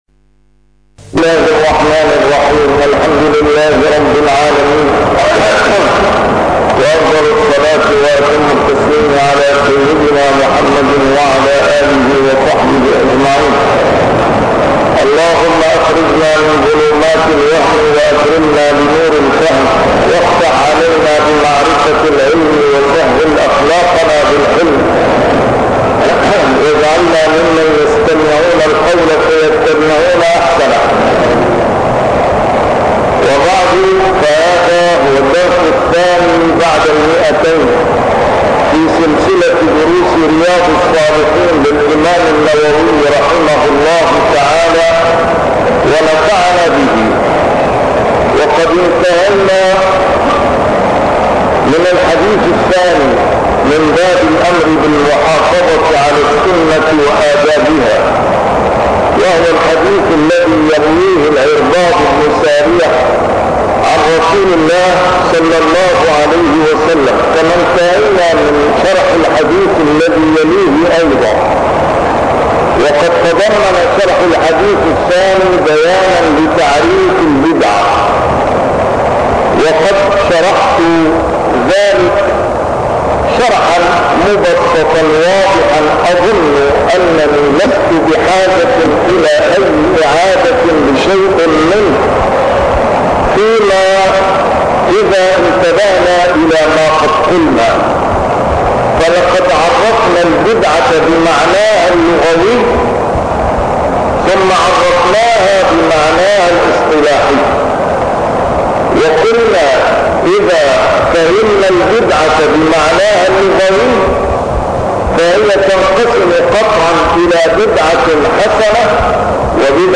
A MARTYR SCHOLAR: IMAM MUHAMMAD SAEED RAMADAN AL-BOUTI - الدروس العلمية - شرح كتاب رياض الصالحين - 202- شرح رياض الصالحين: المحافظة على السنة